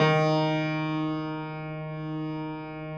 53g-pno07-D1.wav